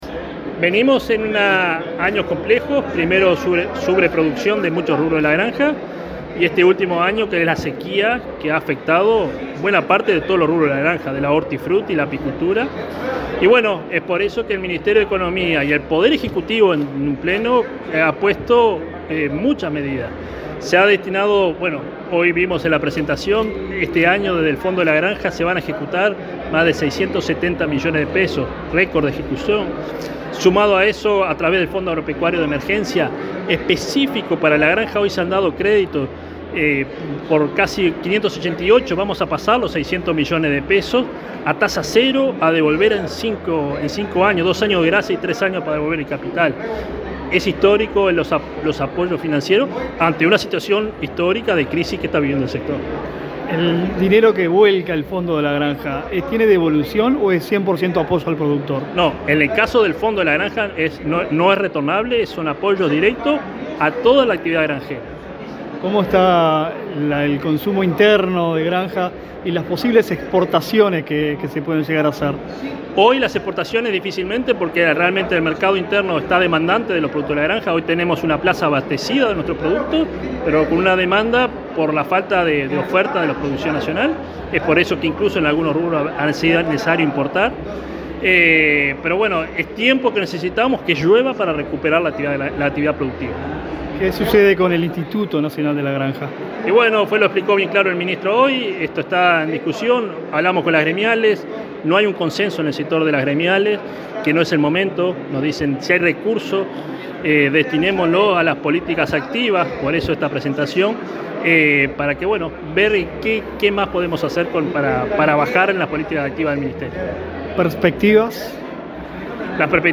Entrevista al director nacional de la Granja
Entrevista al director nacional de la Granja 05/07/2023 Compartir Facebook X Copiar enlace WhatsApp LinkedIn El director nacional de la Granja, Nicolás Chiesa, dialogó con Comunicación Presidencial, en el marco del Primer Congreso Nacional de la Granja, que se lleva a cabo en la ciudad de Trinidad, departamento de Flores.